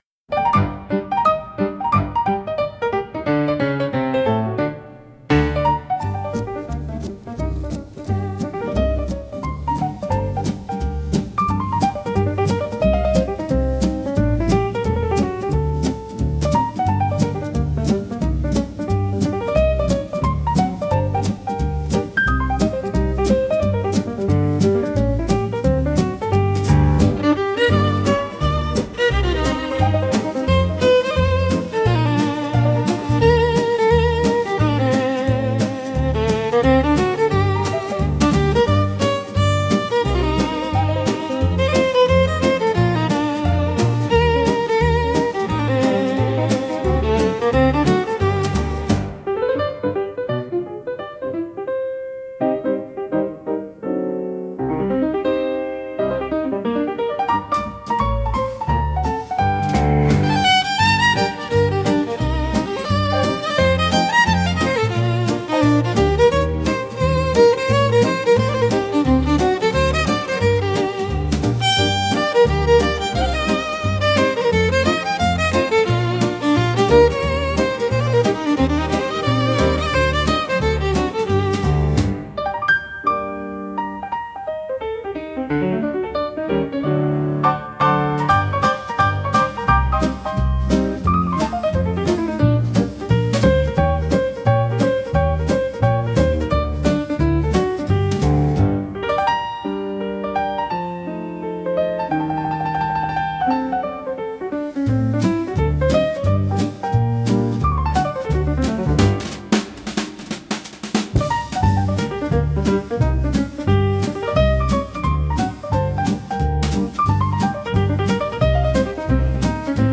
música 2 arranjo: IA) instrumental